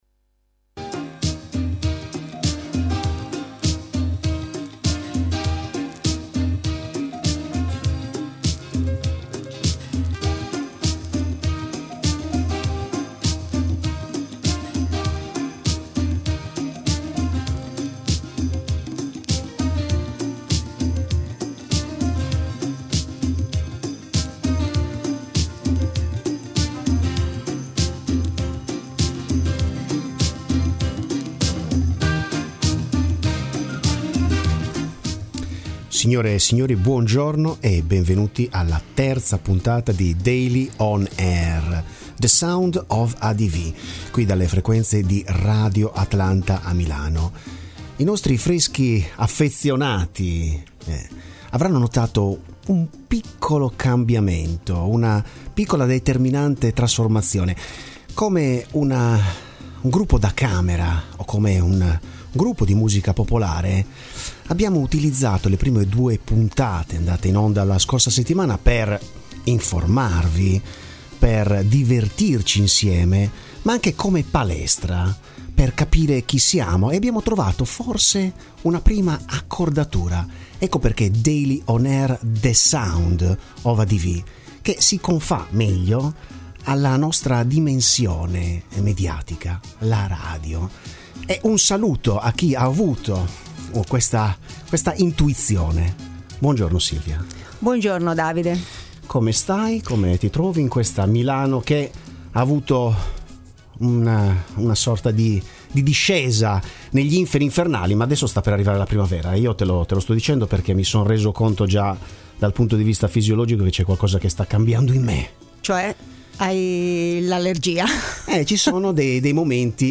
Il mondo della comunicazione nei suoi variegati avvenimenti: pubblicità, imprenditoria, media, tecnologia, mercato, trend. Notizie, opinioni e approfondimenti in 30 minuti ricchi di parole e… musica.